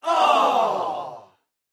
Ооох толпы